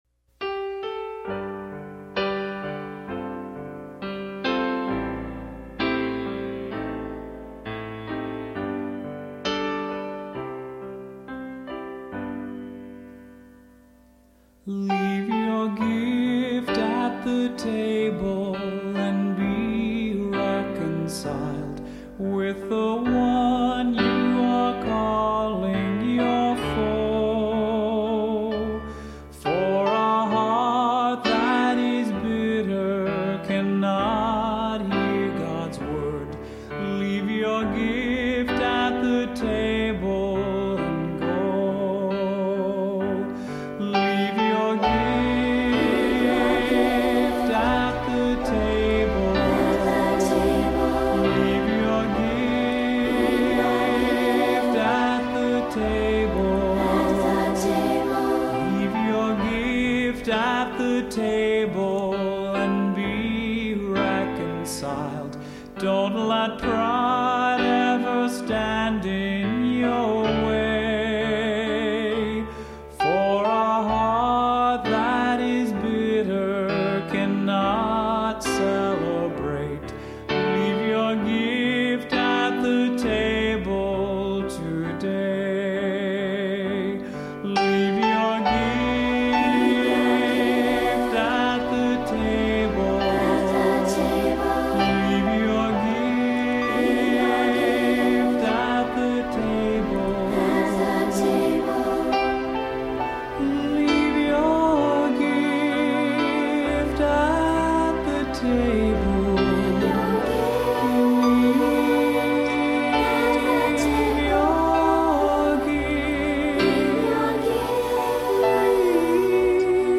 Voicing: Three-Part Children’s Choir, Cantor, Assembly